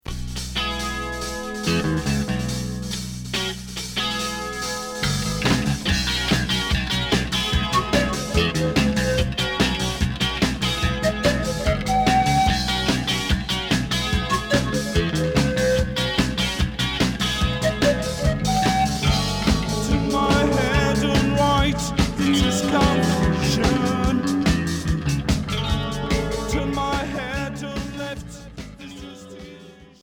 Rock pop Deuxième 45t